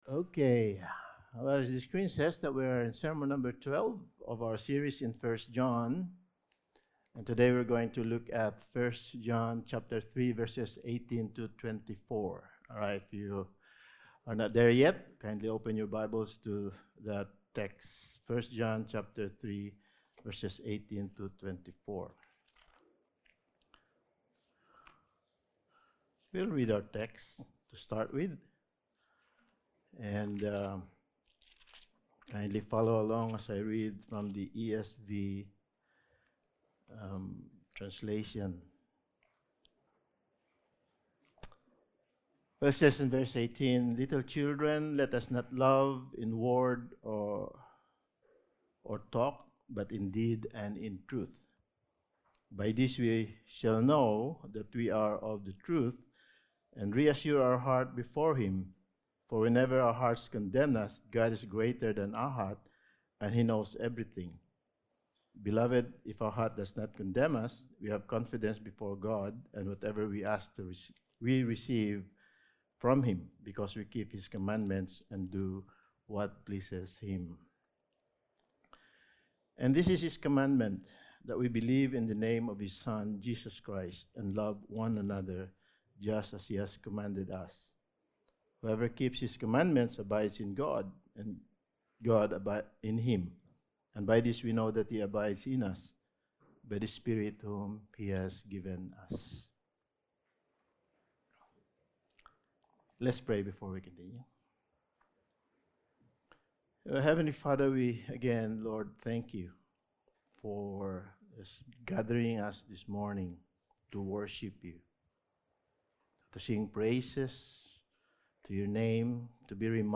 Passage: 1 John 3:18-24 Service Type: Sunday Morning